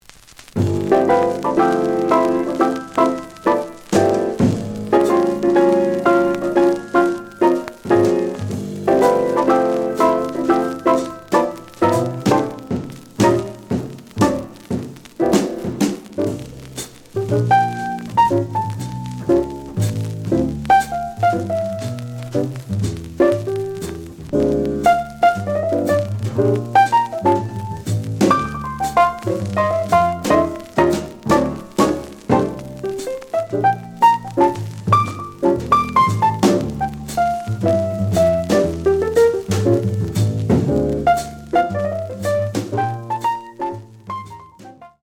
The audio sample is recorded from the actual item.
●Genre: Jazz Funk / Soul Jazz
Some noise on B side.